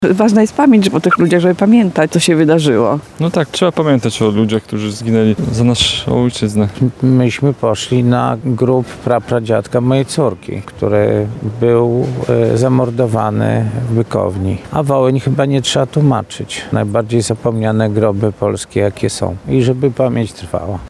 To ważne miejsce na najstarszej lubelskiej nekropolii – mówią odwiedzający cmentarz przy ul. Lipowej w Lublinie, na którym znajdują się obie mogiły.